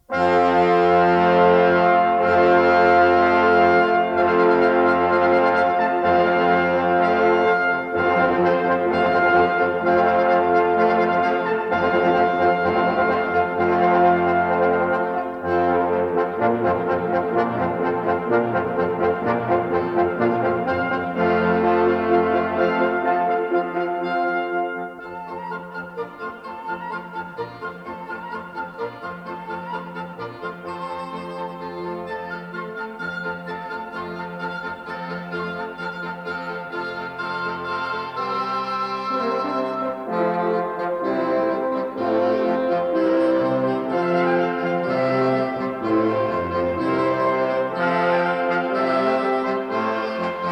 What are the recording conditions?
A 1960 stereo recording